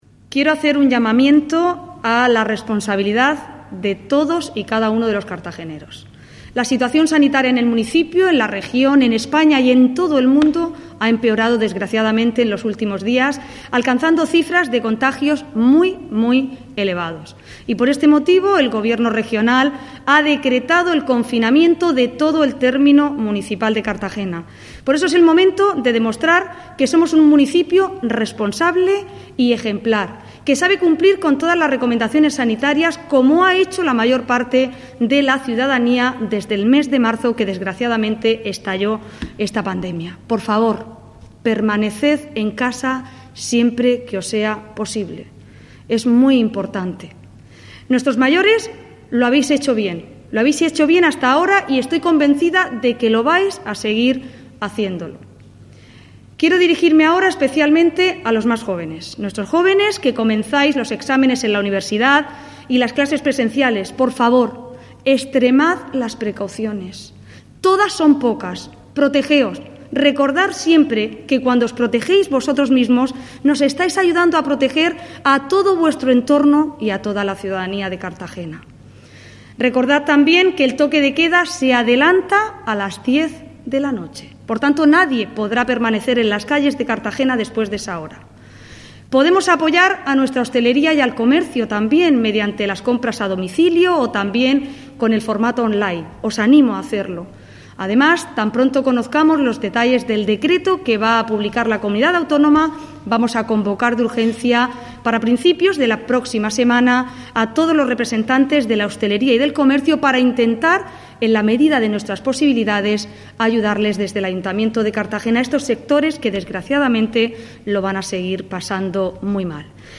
Declaración Ana Belén Castejón.